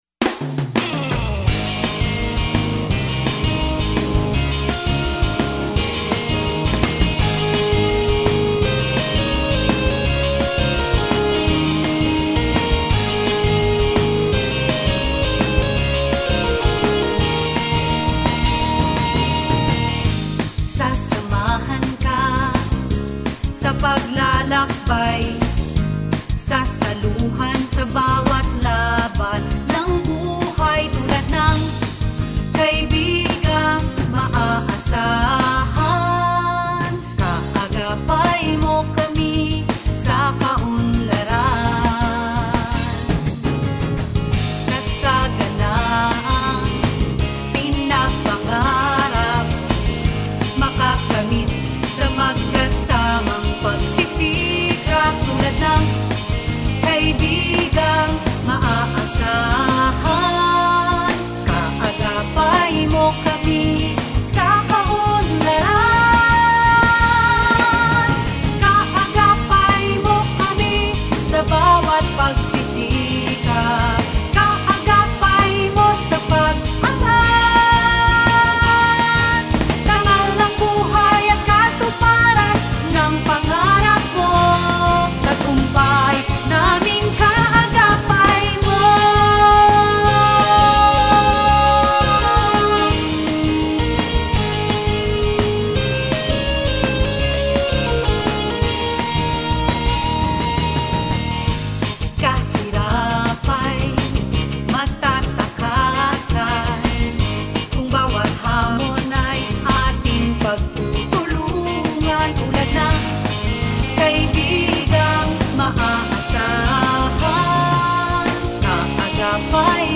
Song
Like any jingle, but without compromising the core message, the lyrics and music of Kaagapay were intentionally made simple and catchy for easy recall and instant participation of the assembly.